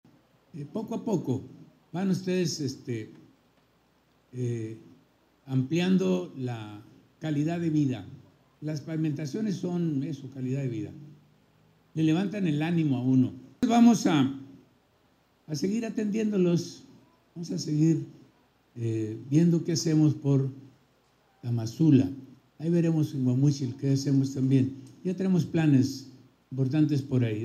CITA-1-AUDIO-GOBERNADOR-RRM-INAUGURACION-CALLE-PEDRO-JUAREZ.mp3